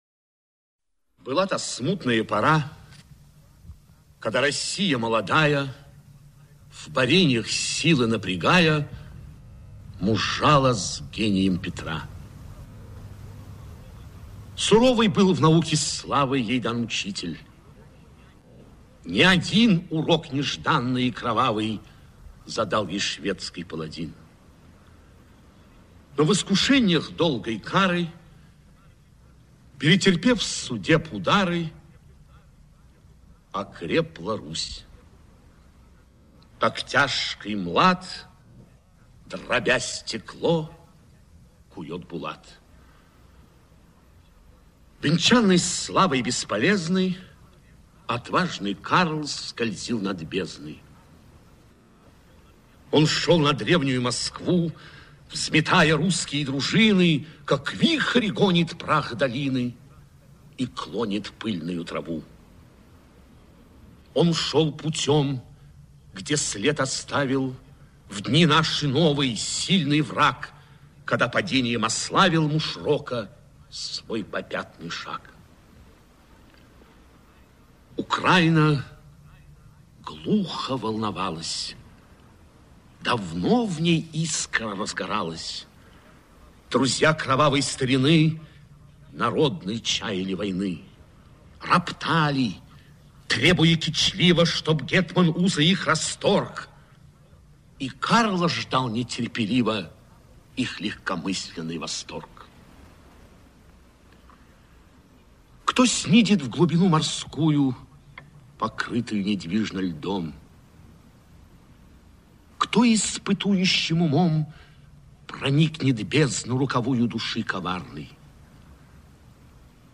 Аудиокнига Полтава
Качество озвучивания весьма высокое.